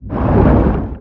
Sfx_creature_chelicerate_exoattack_loop_water_os_05.ogg